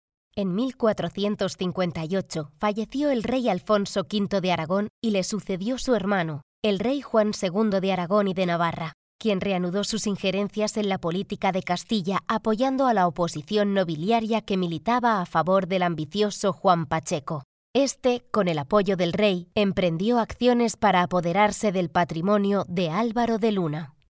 Locutoras españolas y locutores de España. Grupo B
locutor, voice over